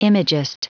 Prononciation du mot imagist en anglais (fichier audio)
Prononciation du mot : imagist